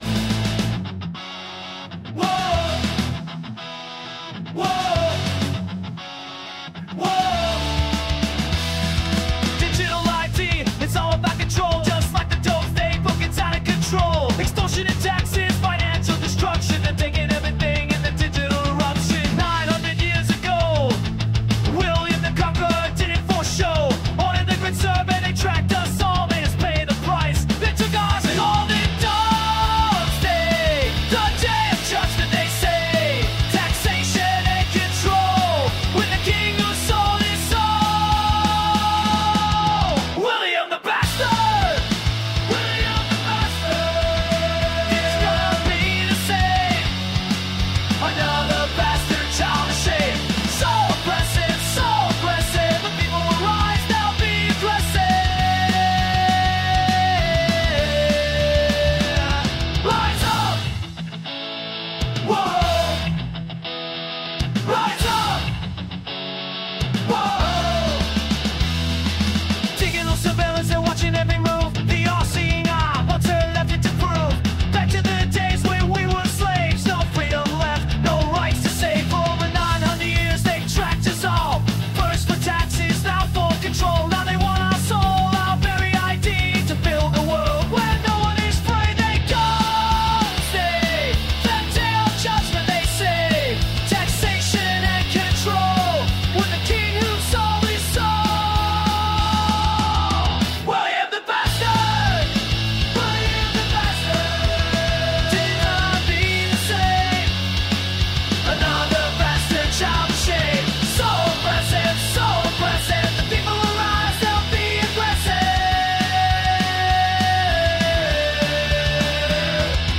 PUNK ROCK VERSION